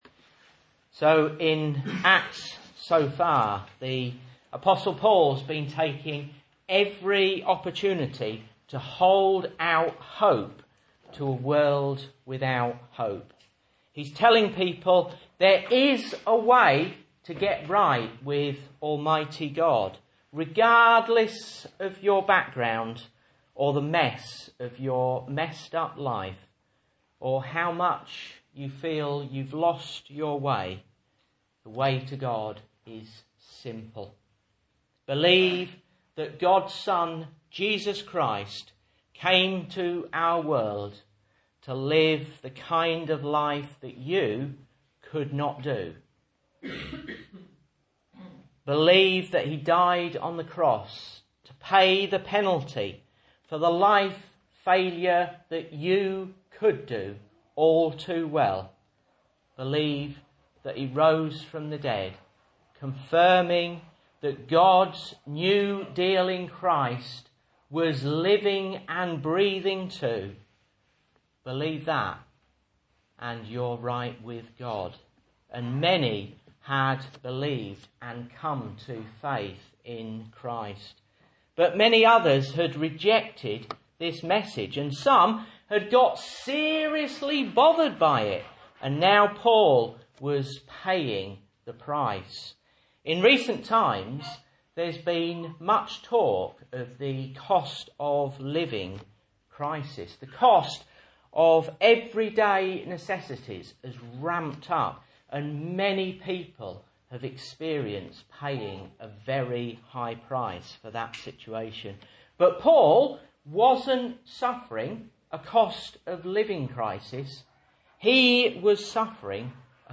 Message Scripture: Acts 27:1-26 | Listen